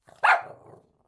动物 西施犬 " 狗狗西施犬吠声单曲06
描述：西施犬，单树皮
Tag: 吠叫 动物 施姿 树皮